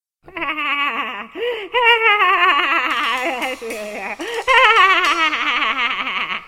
Risada Sem Graça Feminina
Mulher solta uma risada sem graça e forçada.
risada-mulher-sem-graca.mp3